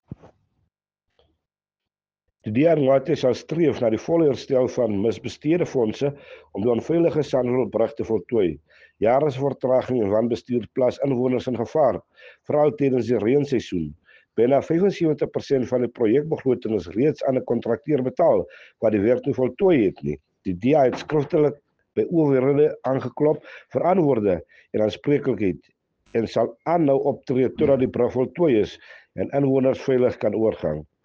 Afrikaans soundbites by Cllr Robert Ferendale and Sesotho soundbite by Jafta Mokoena MPL.